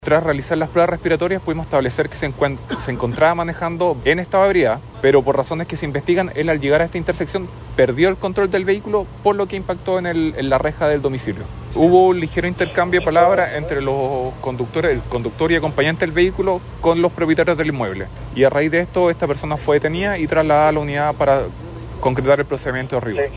cu-trasnoche-1-carabineros.mp3